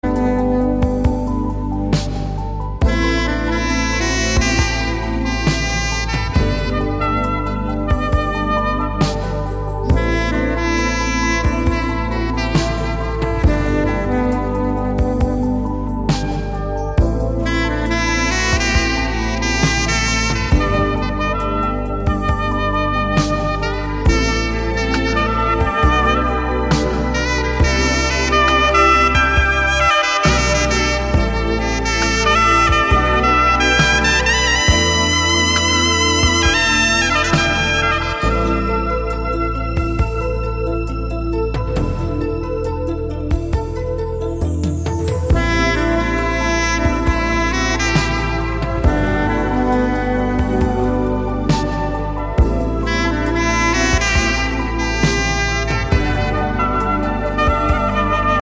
(Không Lời)